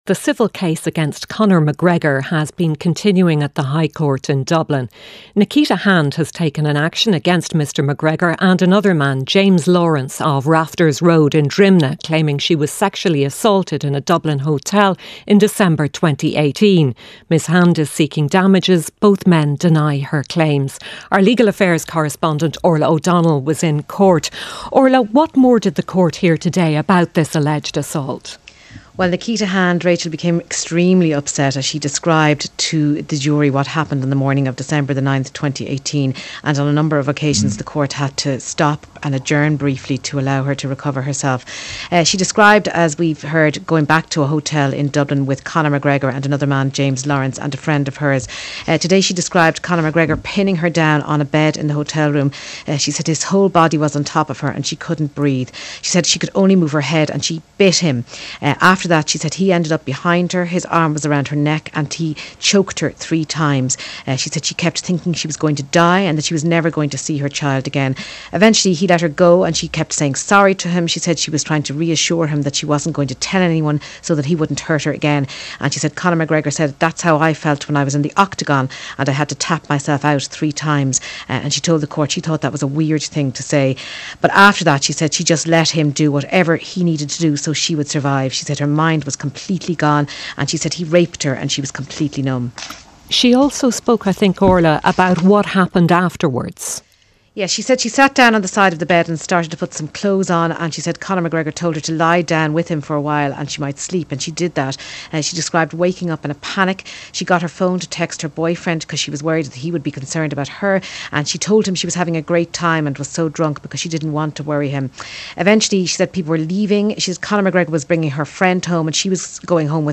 News, sport, business and interviews. Presented by Rachael English. Listen live Monday to Friday at 1pm on RTÉ Radio 1.